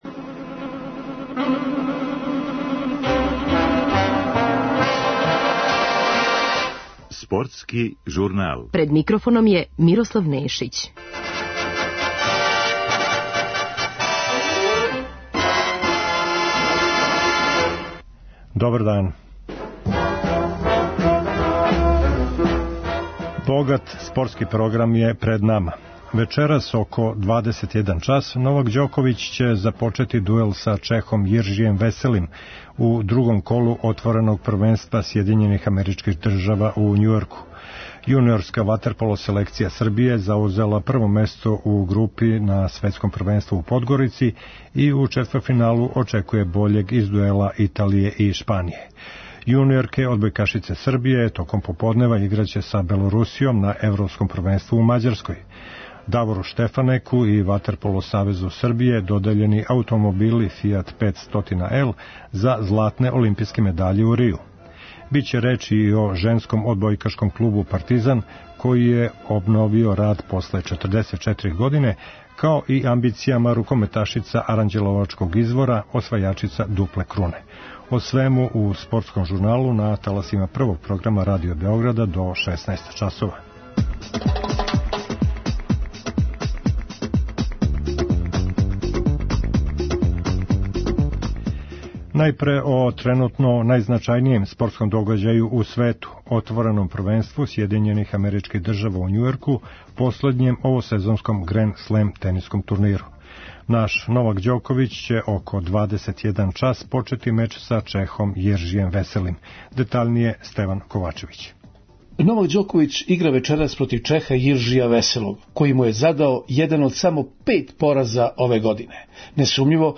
Бићете у прилици да чујете очекивања Новака Ђоковића пред вечерашњи меч другог кола Отвореног првенства Сједињених америчких држава у Њујорку са Чехом Јиржијем Веселим. Говорићемо и о учинку осталих наших представника на последњем овосезонском грен-слем турниру.
Припремили смо репортажу са тог догађаја.